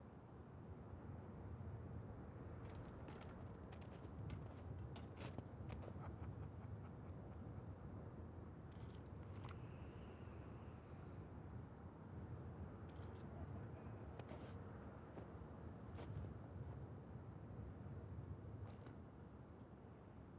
amb_banditbase.wav